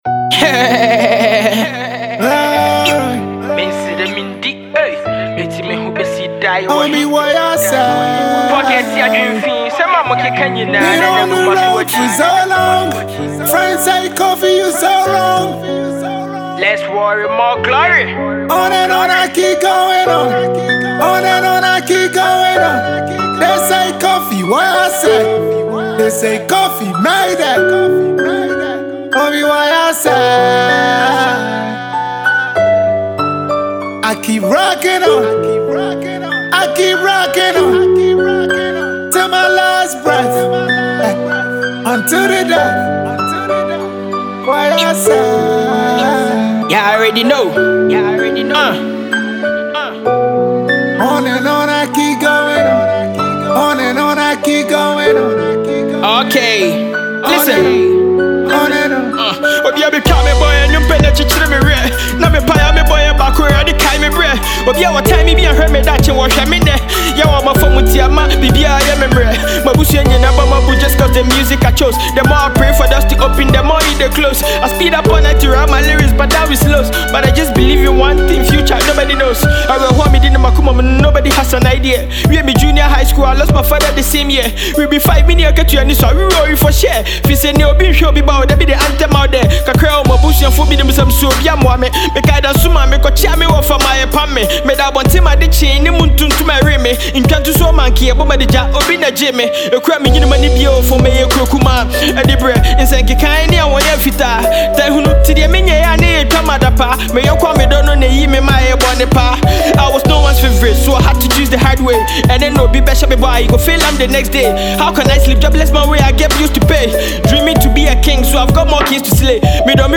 hip-hop
You would love the lyrical flow.